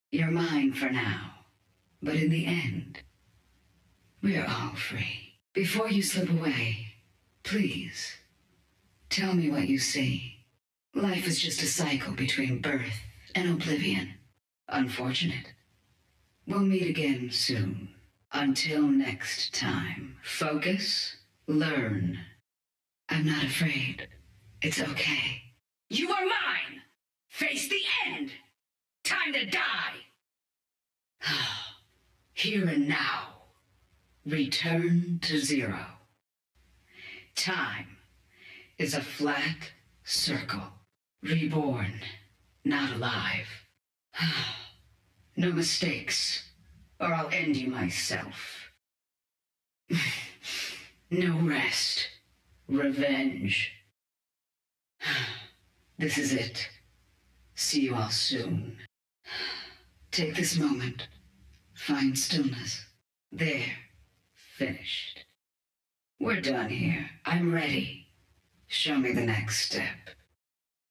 Образец голоса персонажа